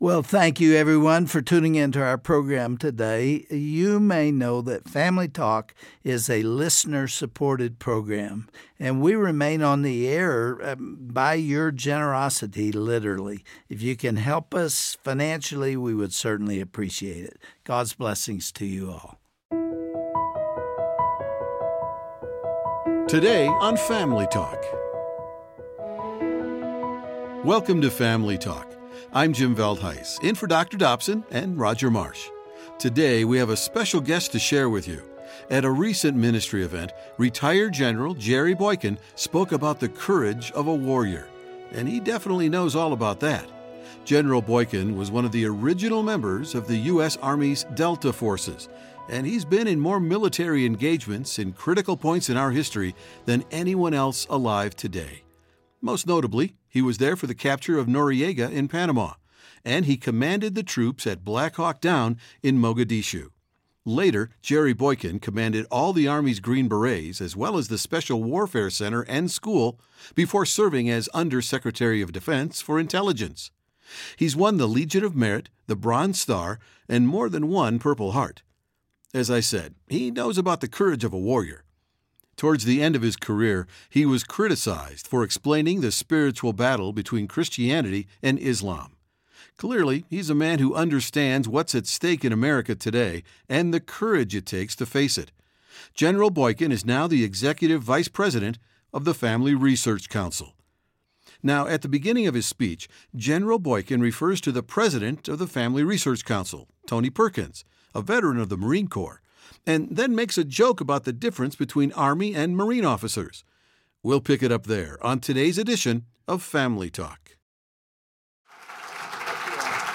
When we think of the courage of a warrior, its easy to think of soldiers in battle, but General Jerry Boykin reminds us of the courage we all need to fight the spiritual battles were facing in the culture today. Learn how to be a warrior for the soul and the heart of this nation in this inspiring broadcast today on Family Talk.